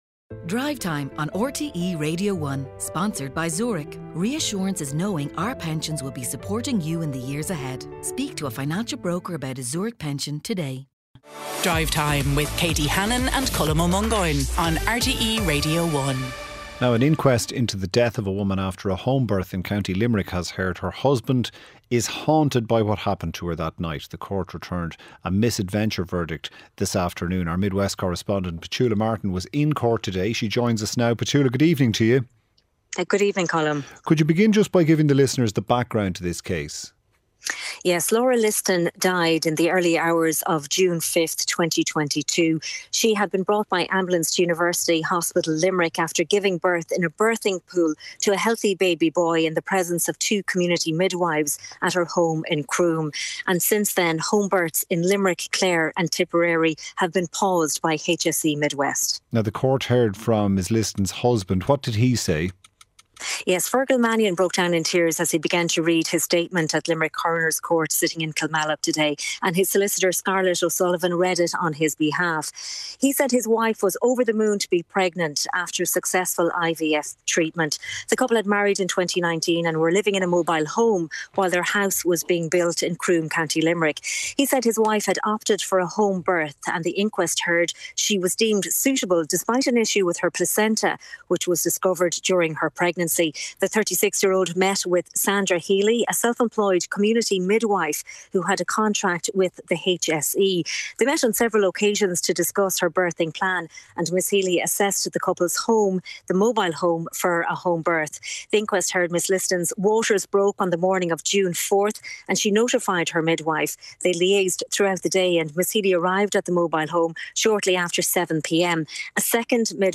Highlights from the daily news programme with Sarah McInerney and Cormac Ó hEadhra. Featuring all the latest stories, interviews and special reports.